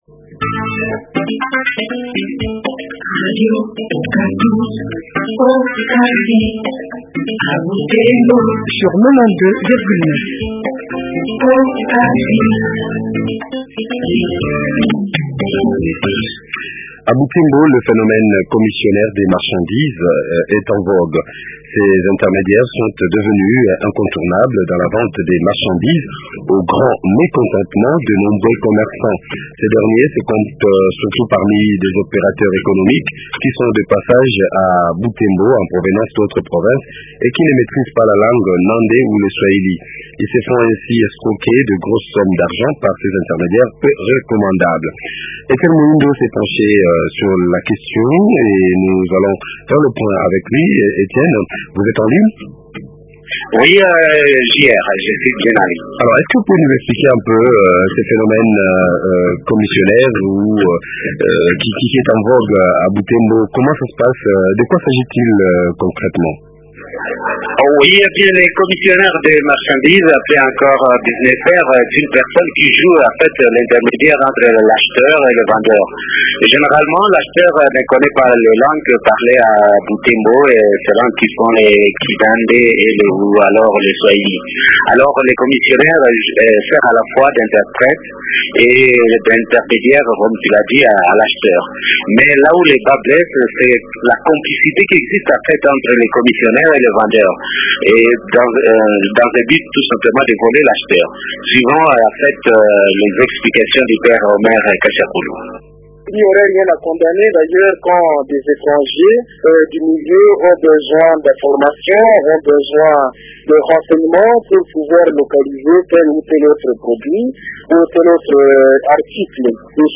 reçoivent Alphosine Losenge, Maire adjointe de la ville de butembo.